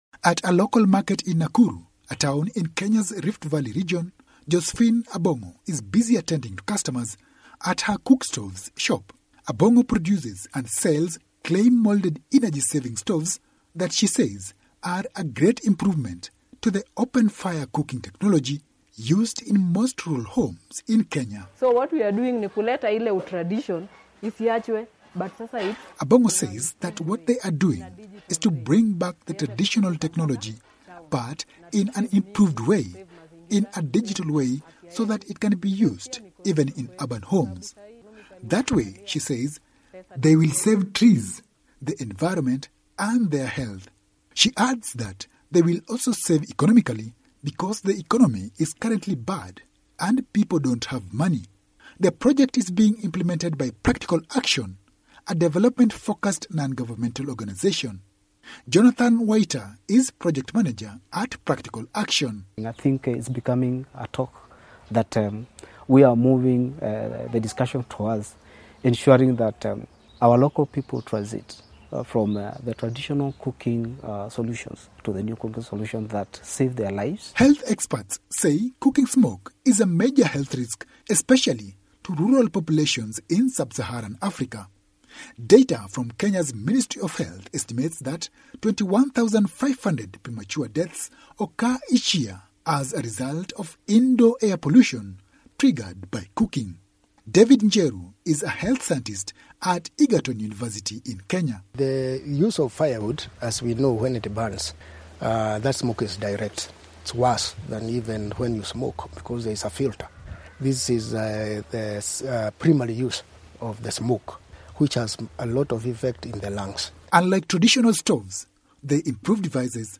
reports from Nakuru, Kenya